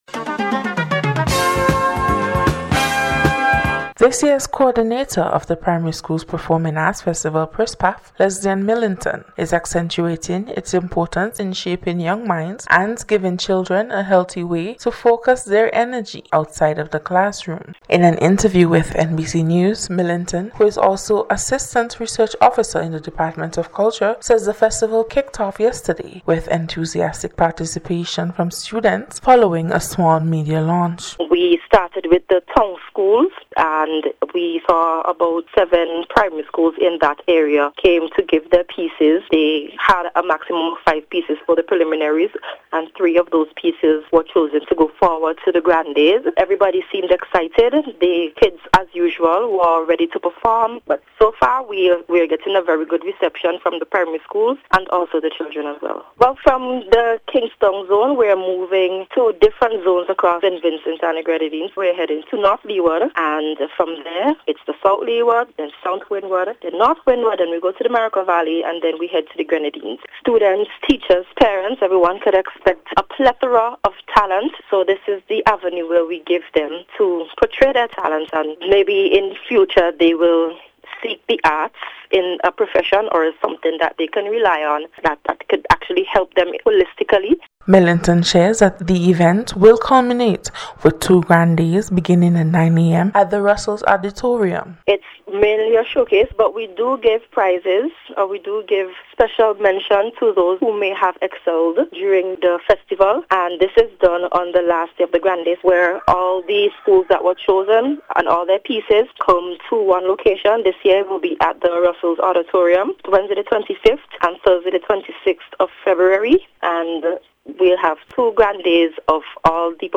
In today’s special report, we hear how this event provides young students with a platform to express themselves